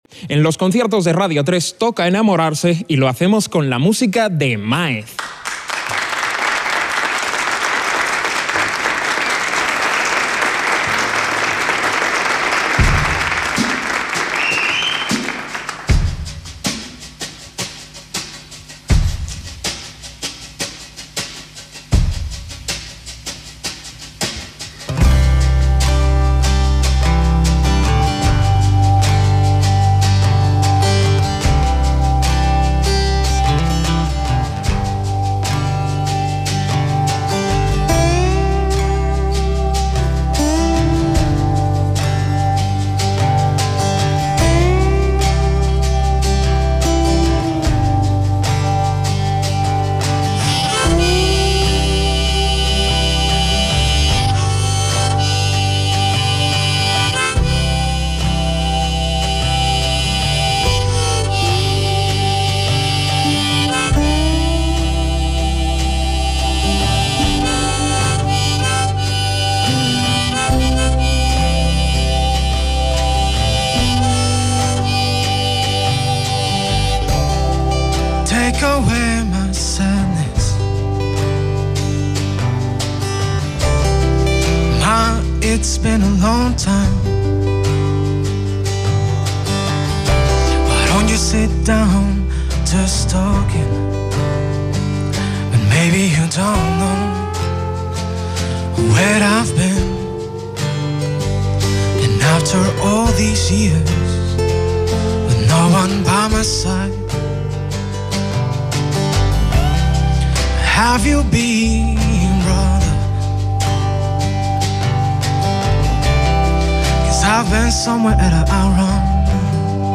neo-Folk